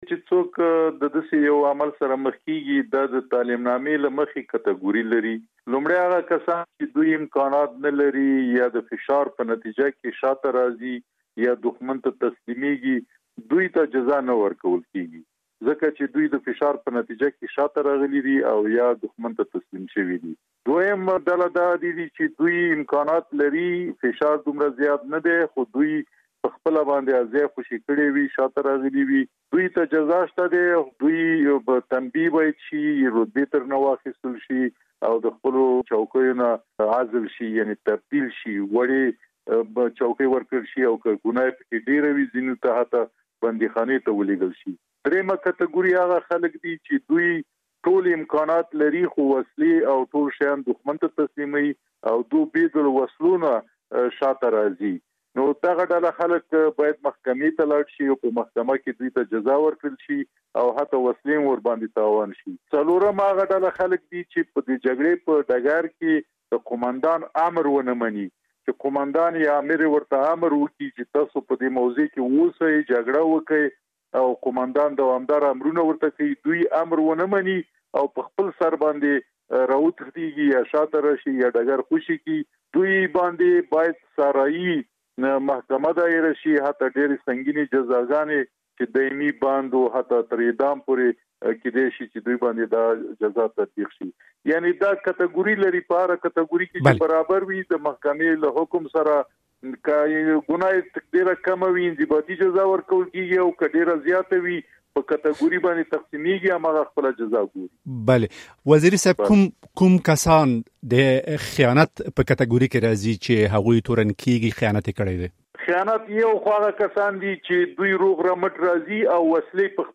د افغانستان دفاع وزارت له پخواني ویاند جنرال دولت وزیري سره مرکه